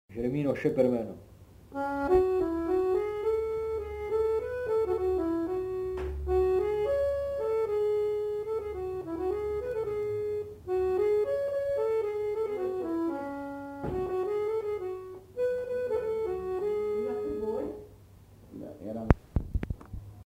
Aire culturelle : Gascogne
Genre : morceau instrumental
Instrument de musique : accordéon chromatique